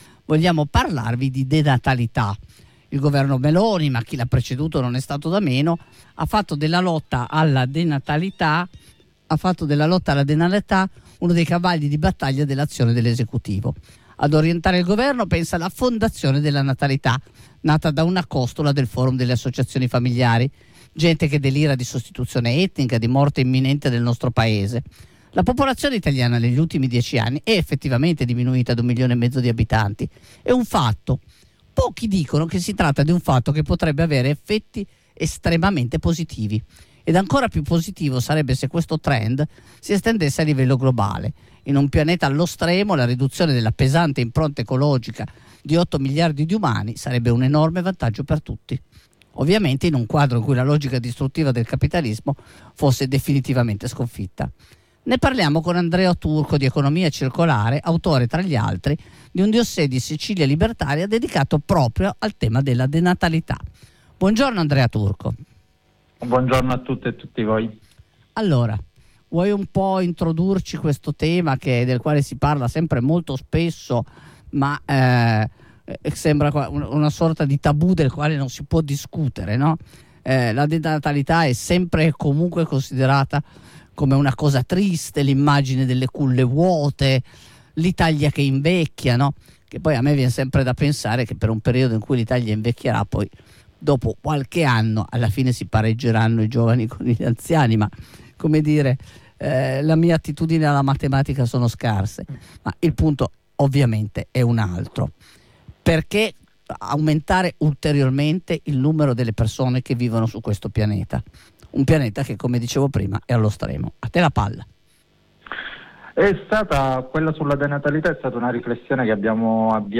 Radio Blackout 105.25FM